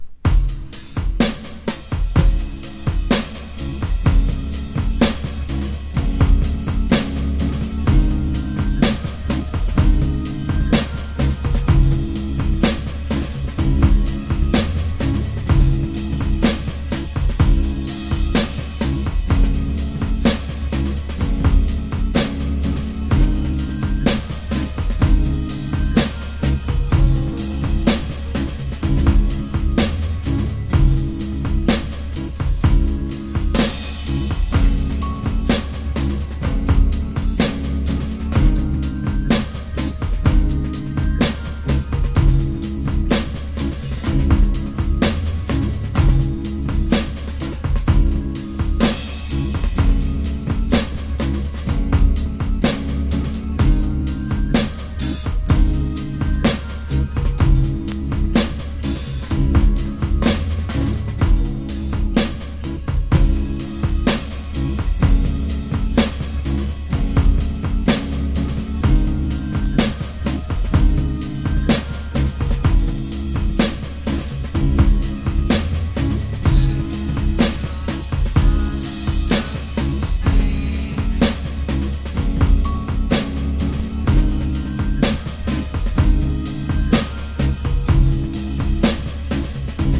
形式 : 7inch / 型番 : / 原産国 : USA
両面共にインストです。
BREAK BEATS / BIG BEAT